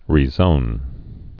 (rē-zōn)